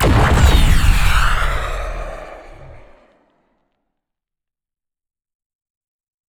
missilea.wav